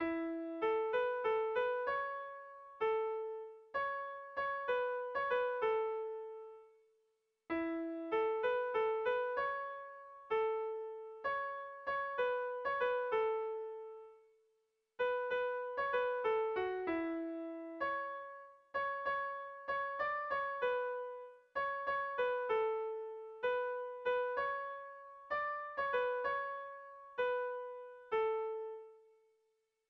Kontakizunezkoa
Zortziko txikia (hg) / Lau puntuko txikia (ip)
AAB